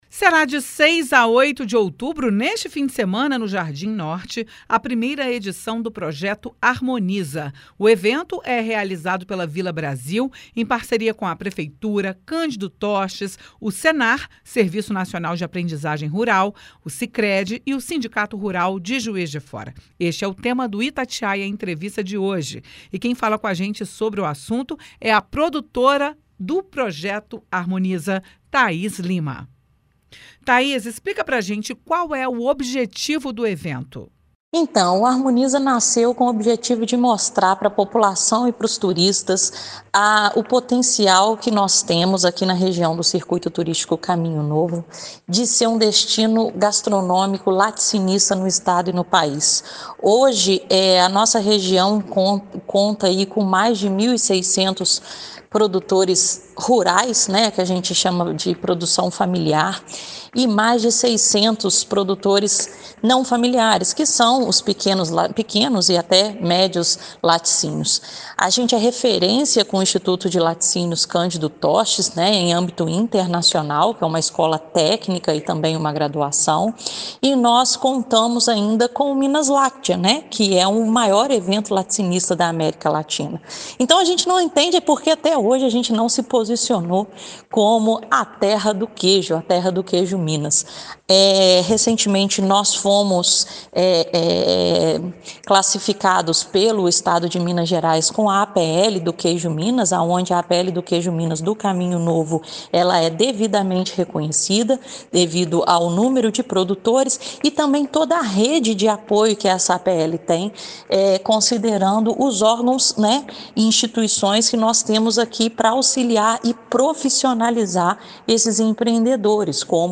Este é o tema do Itatiaia Entrevista desta quarta-feira (4).